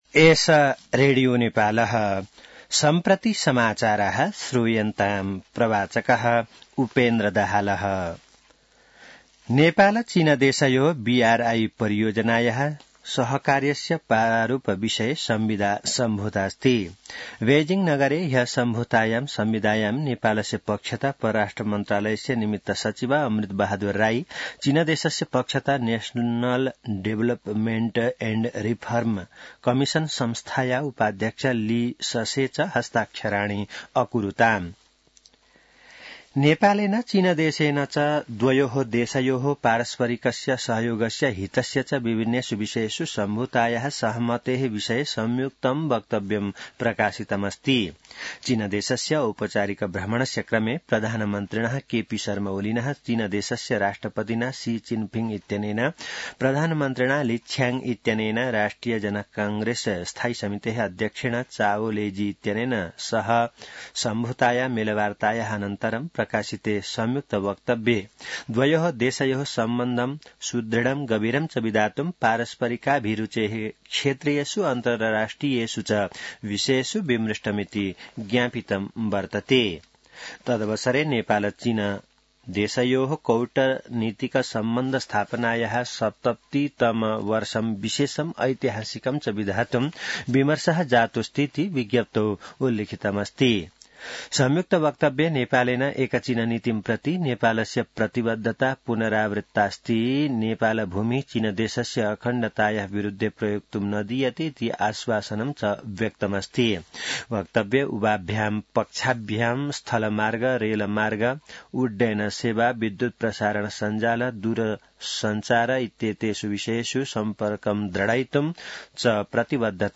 संस्कृत समाचार : २१ मंसिर , २०८१